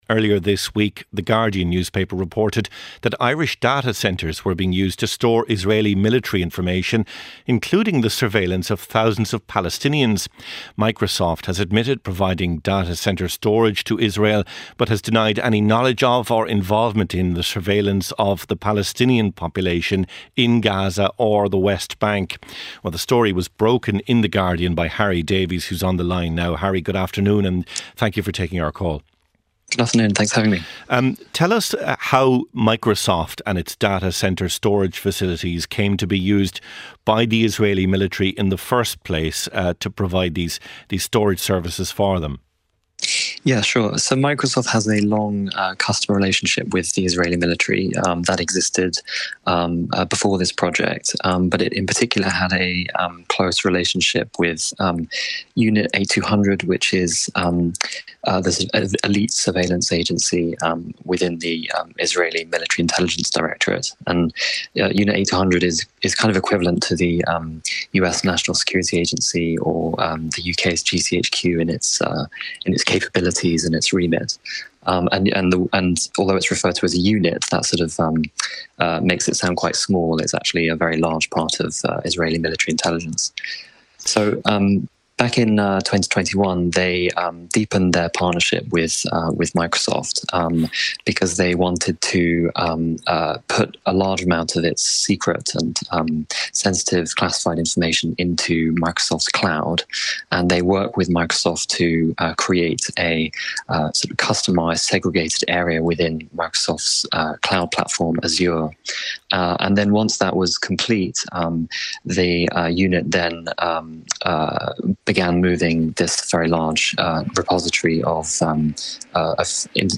Listen live every Sunday at 1pm on RTÉ Radio 1.